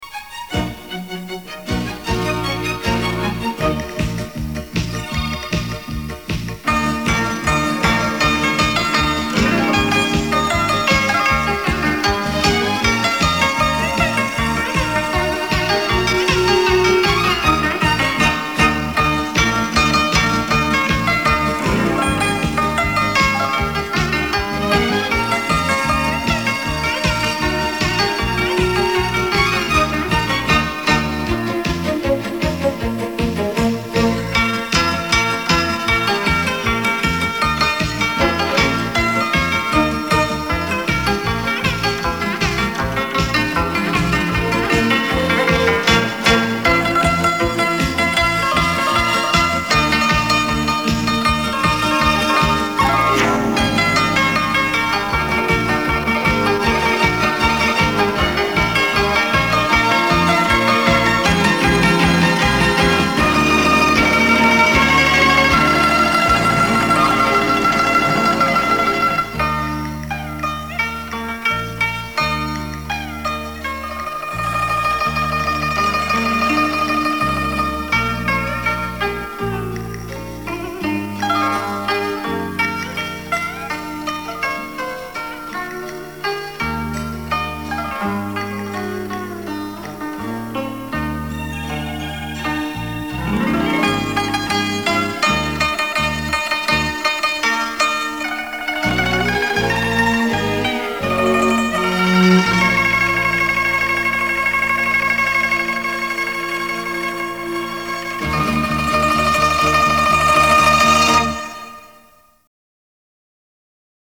古筝、琵琶、二胡、民乐合奏曲